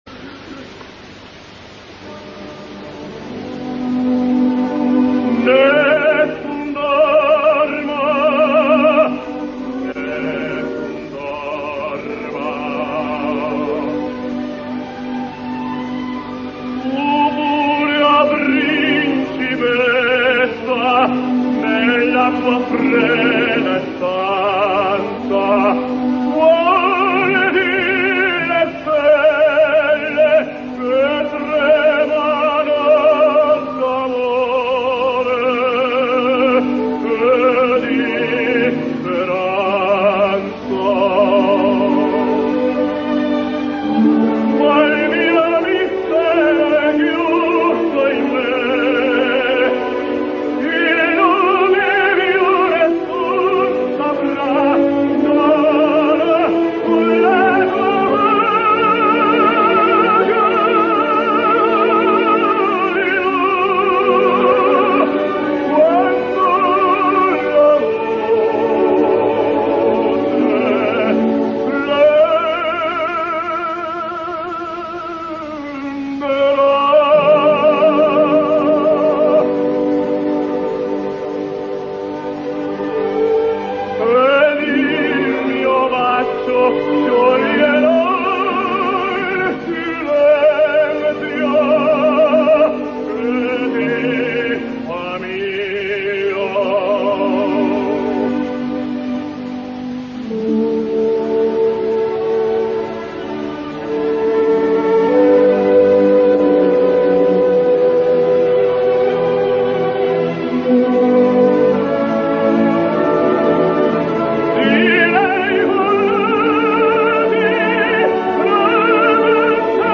Tenors singing Nessun dorma